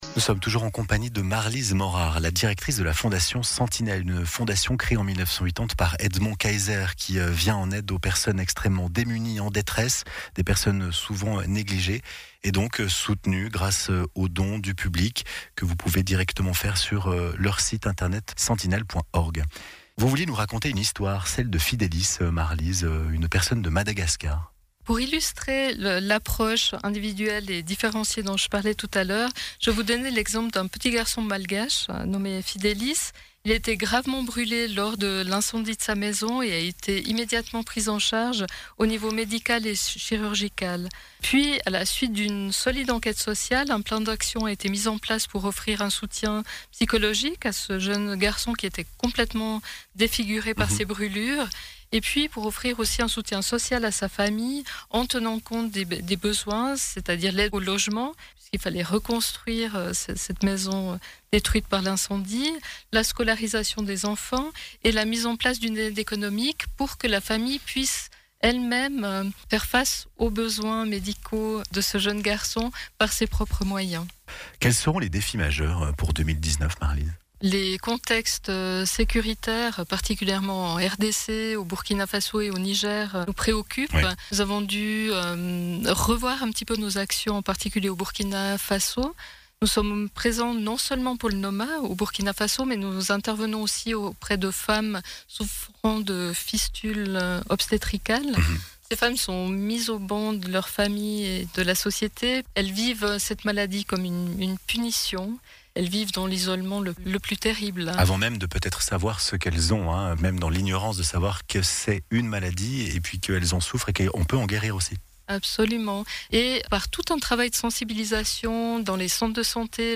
INTERVIEW
sur les ondes de lfm, radio lausannoise